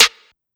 Waka SNARE ROLL PATTERN (46).wav